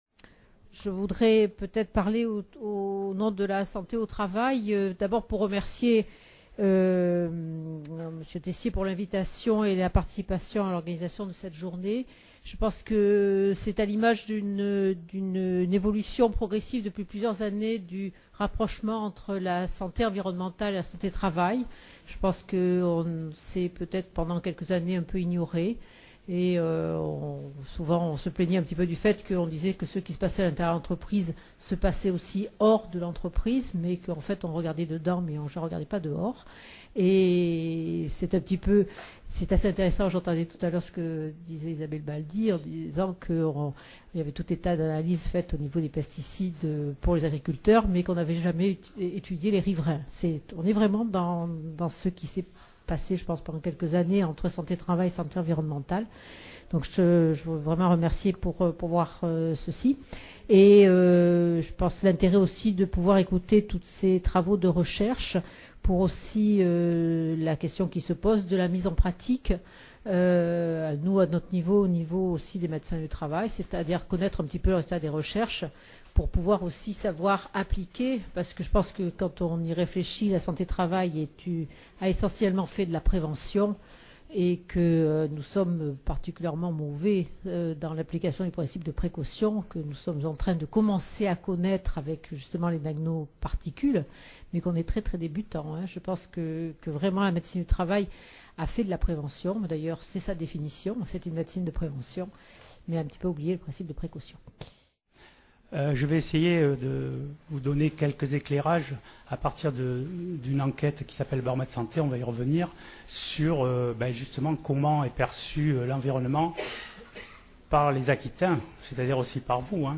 Table Ronde : Précaution, prévention et gestion des risques environnementaux | Canal U